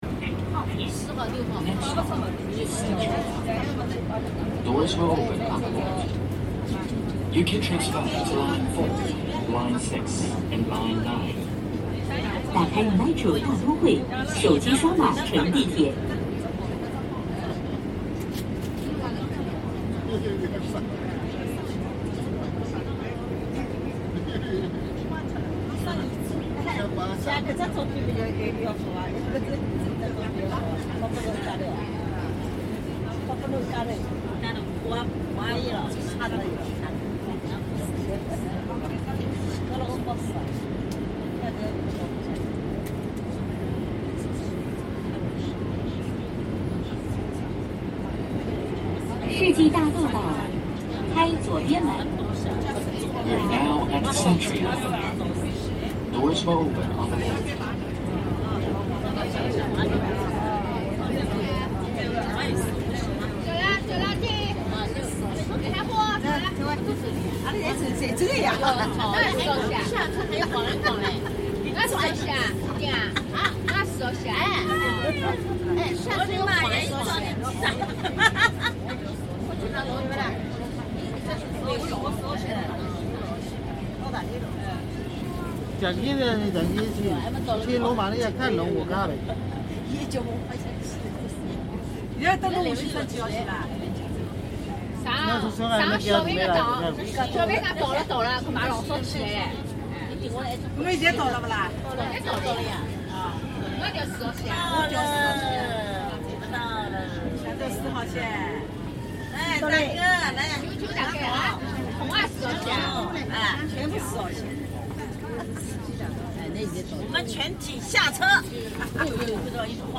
Unlike the crowded noise of European subways, here the sounds were hushed: soft footsteps, the occasional laugh, or a whisper passing between friends.
And yet, I found comfort in the station announcements — their calm rhythm, their clarity. A kind of mechanical poetry that grounded me in the flow of an unfamiliar city.